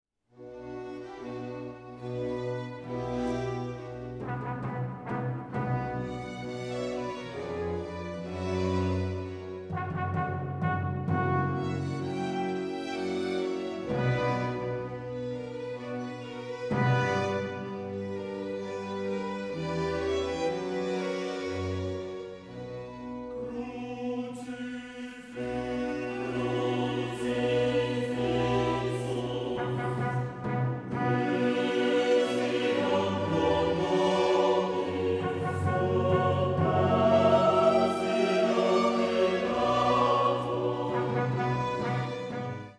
The words from “Crucifixus” through “Et sepultus est” are set quietly and gravely for the trio of soprano, alto, and bass in the minor mode, with timpani and muted trumpets, accompaniment borrowed from mid-century Viennese funeral music.
I think Leopold lifted more than the trumpets.